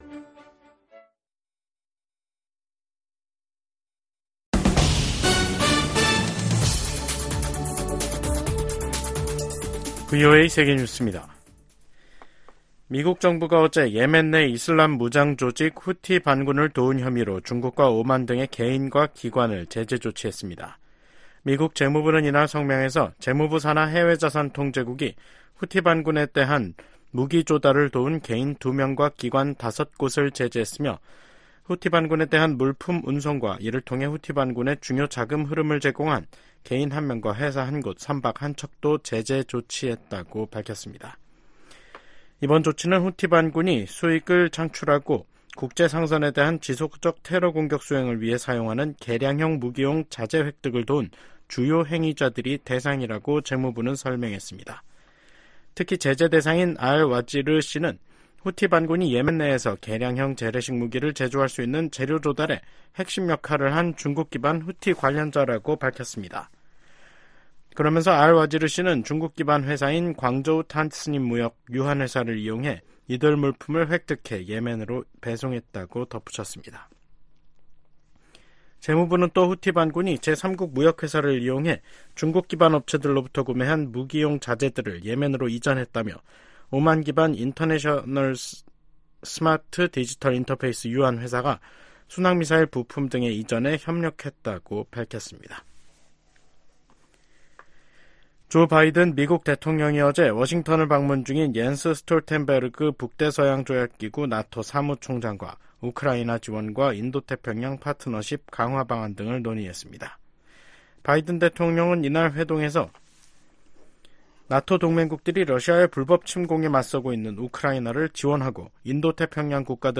VOA 한국어 간판 뉴스 프로그램 '뉴스 투데이', 2024년 6월 18일 2부 방송입니다. 미 국무부는 블라디미르 푸틴 러시아 대통령의 방북과 두 나라의 밀착이 미국뿐 아니라 국제사회가 우려하는 사안이라고 지적했습니다. 미국의 전문가들은 러시아가 푸틴 대통령의 방북을 통해 탄약 등 추가 무기 지원을 모색하고 북한은 식량과 경제 지원, 첨단 군사기술을 얻으려 할 것이라고 분석했습니다.